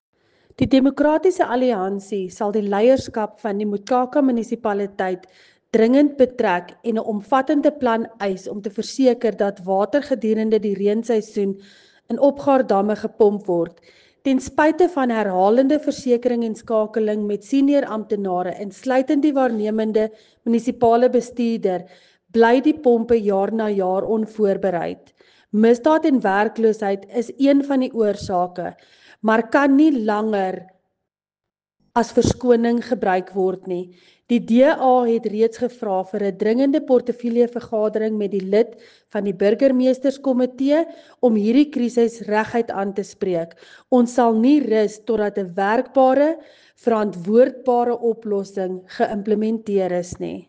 Afrikaans soundbite by Cllr Linda Louwrens and